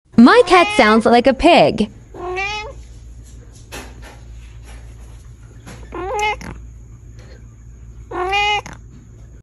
My cat sounds like a sound effects free download
My cat sounds like a little pig